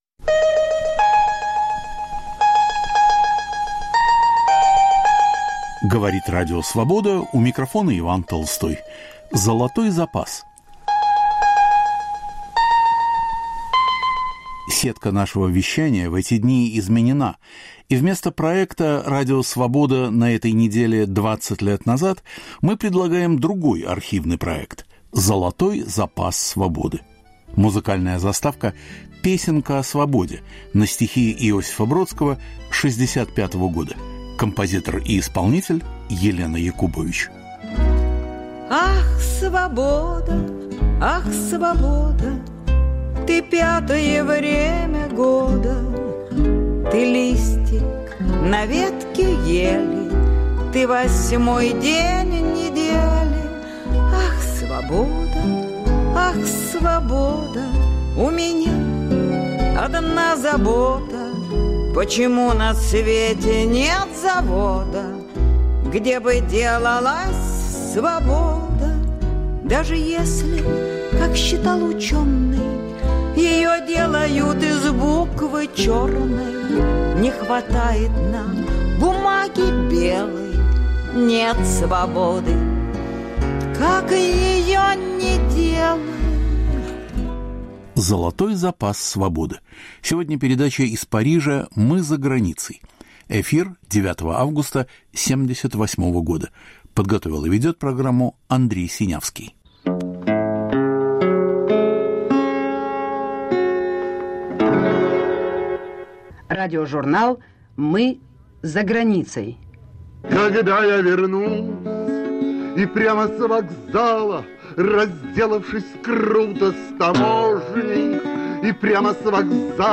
Передача из Парижа "Мы за границей".
Ведет Андрей Синявский. Студенческий театральный спектакль по "Реквиему" Анны Ахматовой.